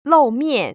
[lòu//miàn] 로우미앤